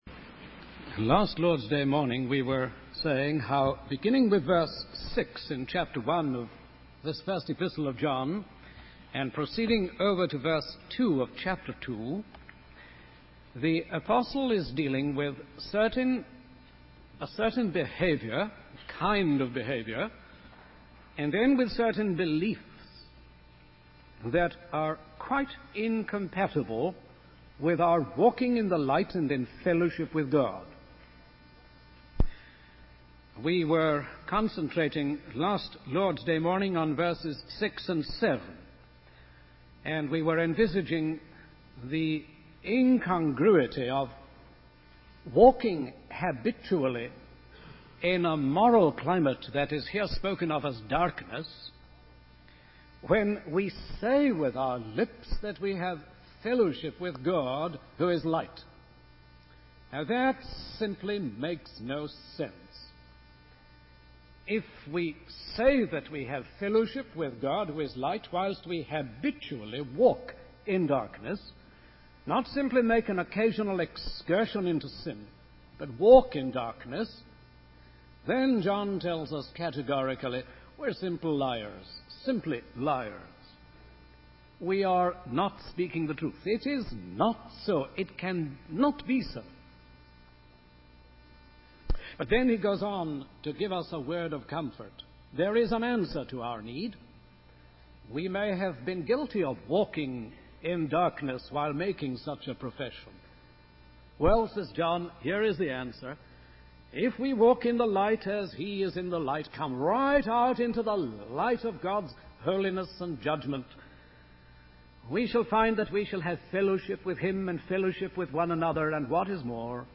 In this sermon, the preacher explores the nature of God as described by John in the Bible.